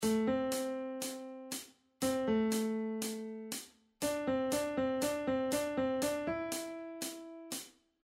Очень мало нот, но с дополнениями.